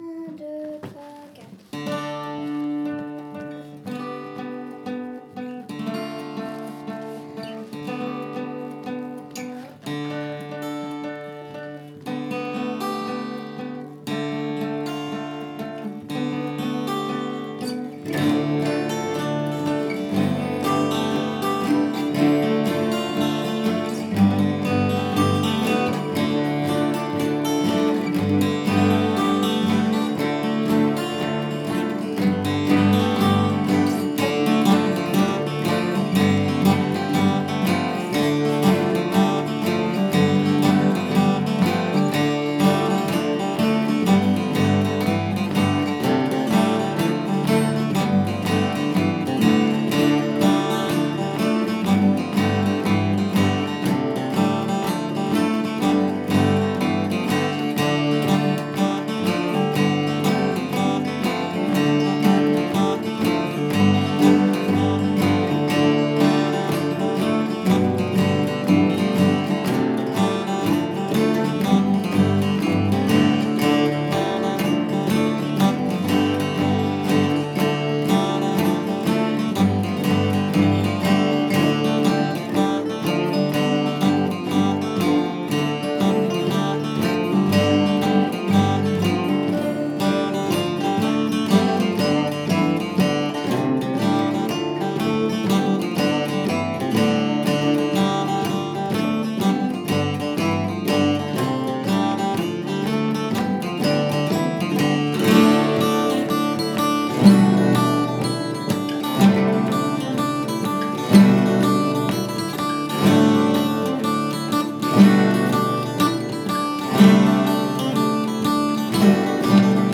07_studio_guitare-laride.mp3